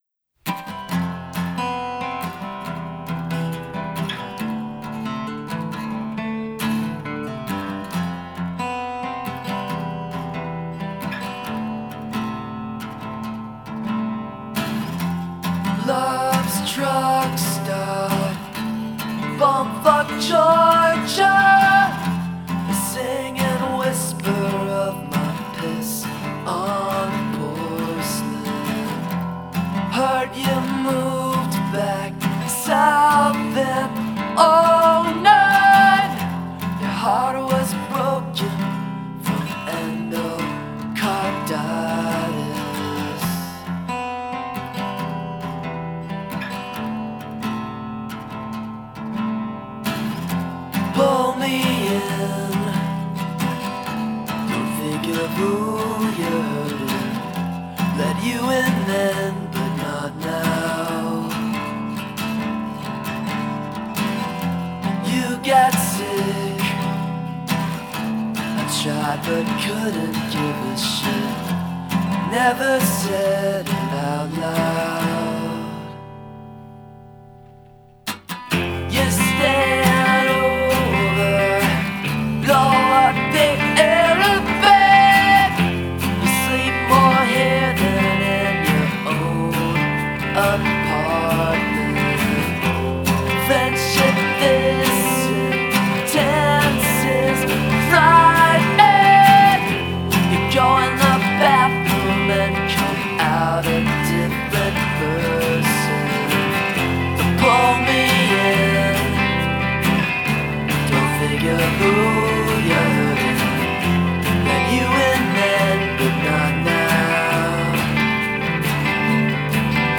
hit like an emotional migraine of sharp pains and dull aches